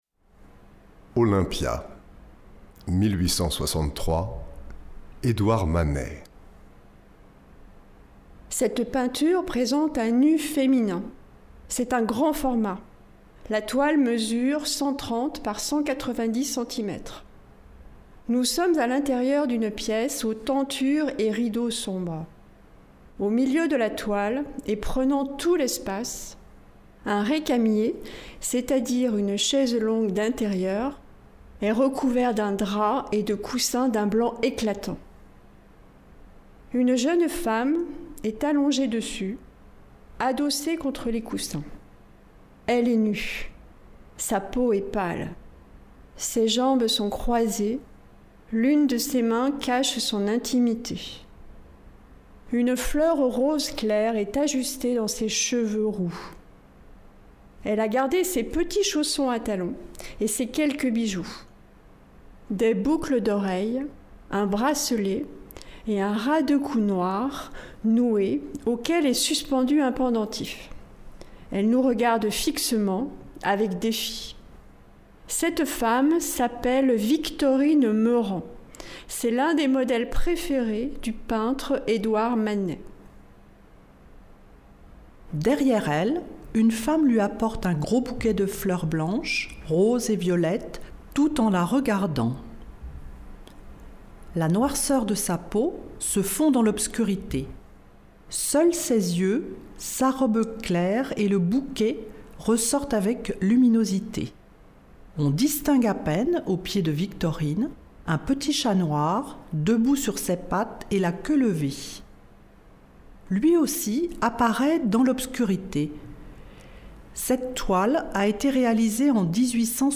Prêtez votre voix à la lecture d’une œuvre d’art pour des personnes malvoyantes – Mars 2018
Une douzaine de collaborateurs ont prêté leur voix à la lecture de quatre nouvelles oeuvres d’art afin de les rendre accessibles à un public malvoyant.